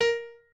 pianoadrib1_48.ogg